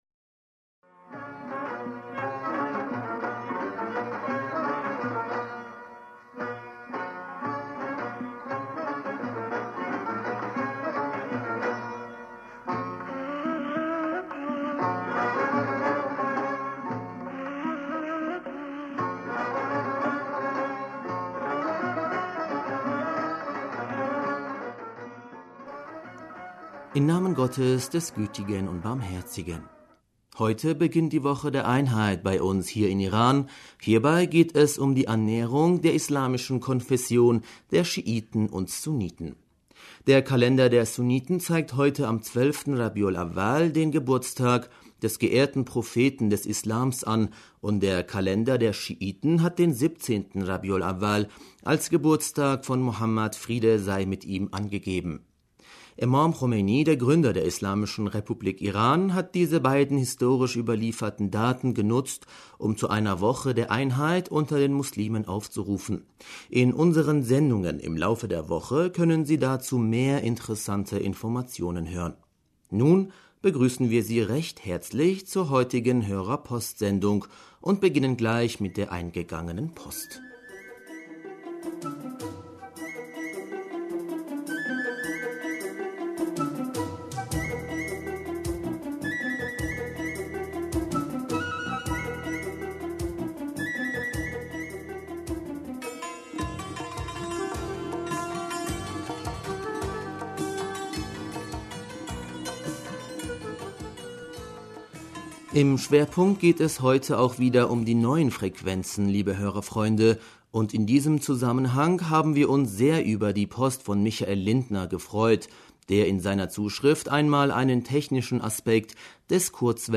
Hörerpostsendung am 10. November 2019